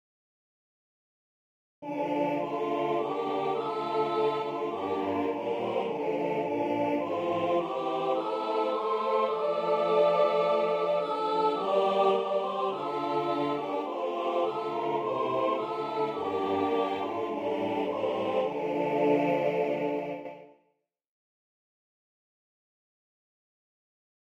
A sacrament hymn